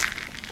default_gravel_footstep.2.ogg